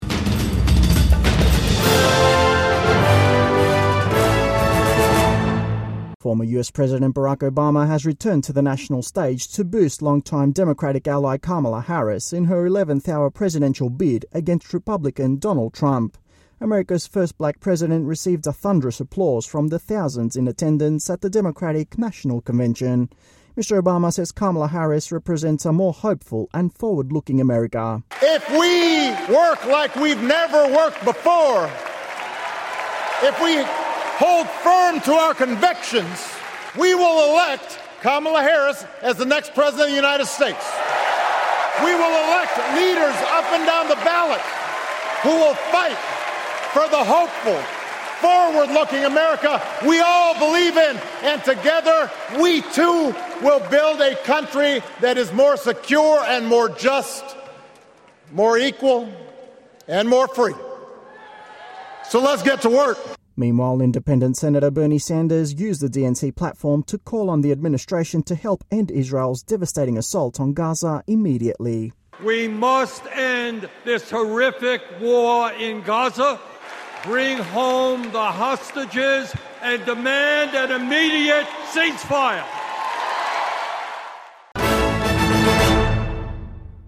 Former US President Barack Obama hails Kamala Harris during DNC speech